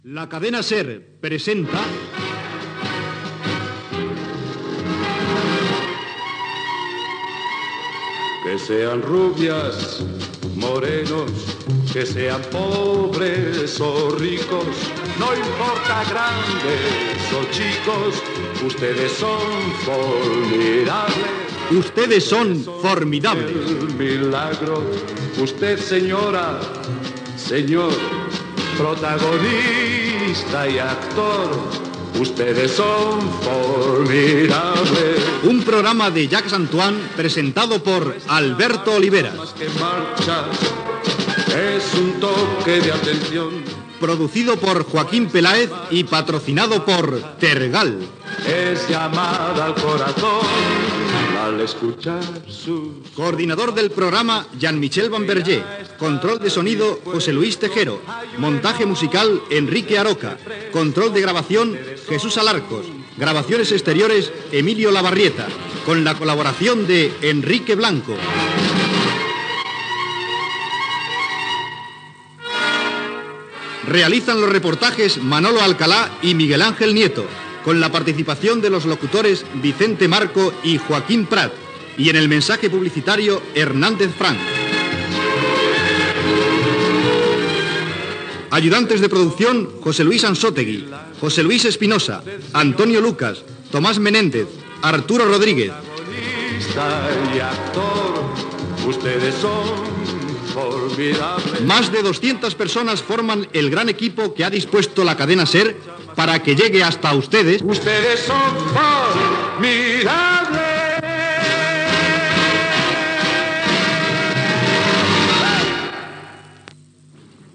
Careta cantada del programa amb els noms de l'equip.